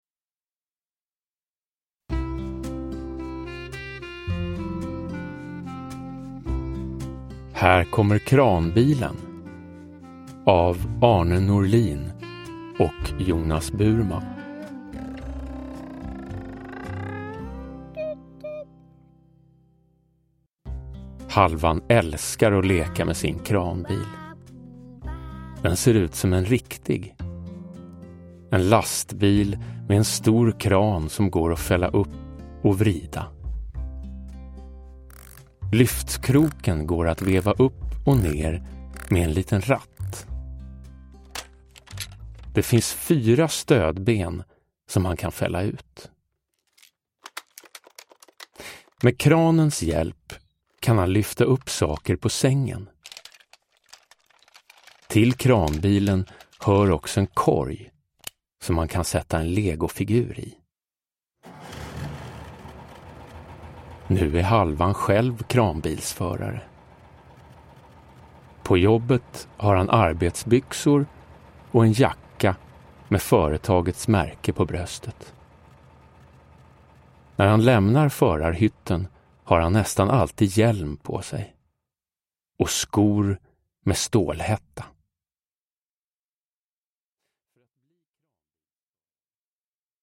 Här kommer kranbilen – Ljudbok
Uppläsare: Jonas Karlsson